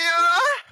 flashlightoff.wav